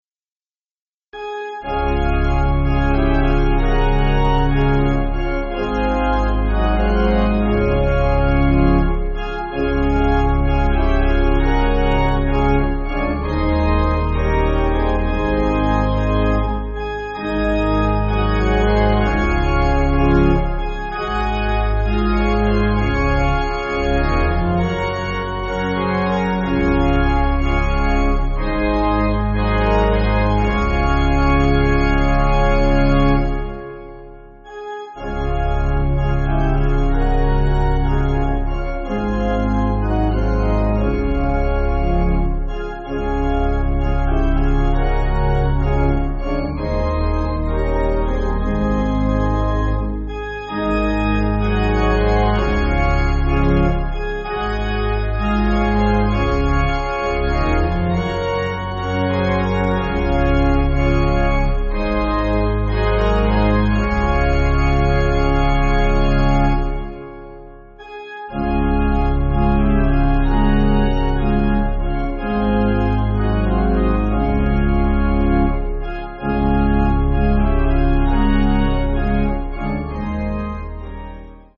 Organ
(CM)   3/Db